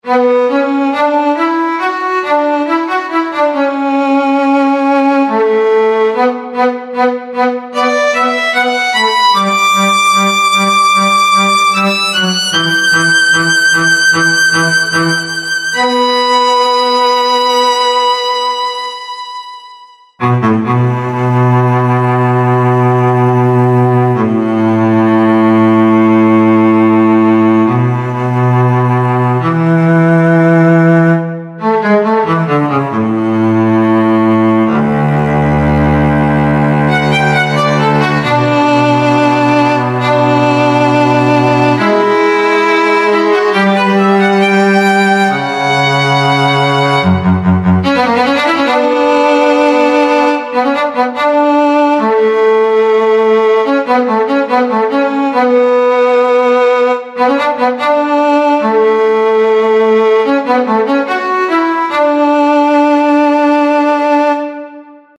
HALion6 : Studio Strings
Violin Case